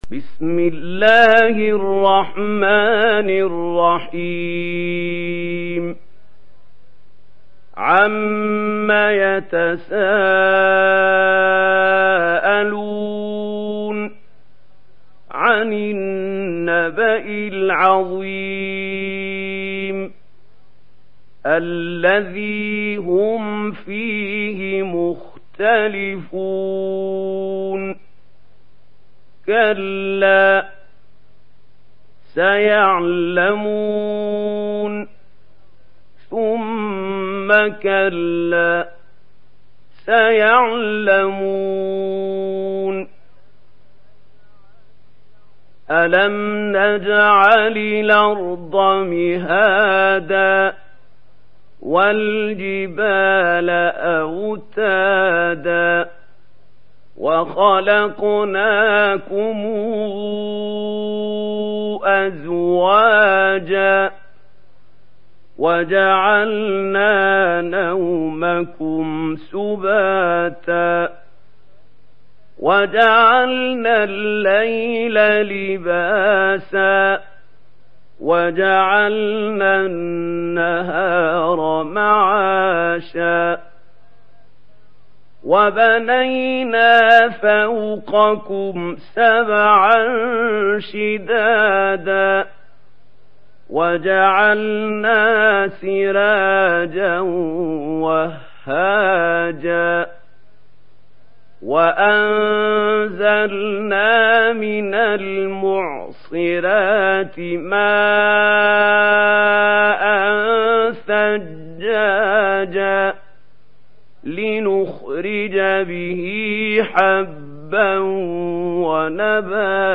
دانلود سوره النبأ mp3 محمود خليل الحصري روایت ورش از نافع, قرآن را دانلود کنید و گوش کن mp3 ، لینک مستقیم کامل